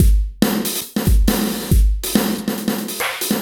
E Kit 27.wav